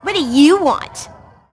Worms speechbanks
LEAVEMEALONE.WAV